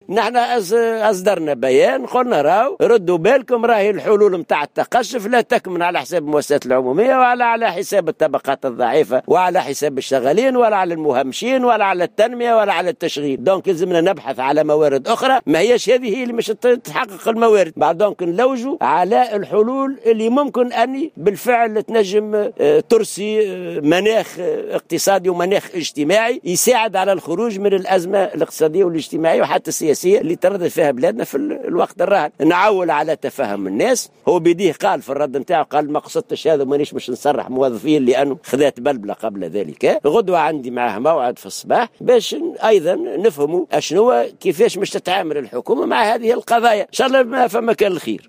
وأضاف العباسي في تصريح إعلامي على هامش انعقاد الهيئة الإدارية لاتحاد الشغل اليوم بمدينة الحمامات أن رئيس الحكومة يوسف الشاهد استدرك بخصوص تصريحاته المتعلقة بإتباع سياسة التقشف وتسريح الموظفين والتفويت في بعض المؤسسات العمومية، مشيرا إلى أن لقاء مرتقبا سيجمعه غدا بالشاهد لمعرفة كيفية تعامل الحكومة مع هذه القضايا.